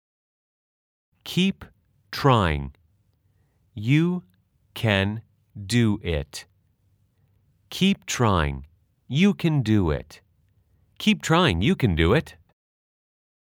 / 입 츠롸잉 / / 유큰 두우윗 /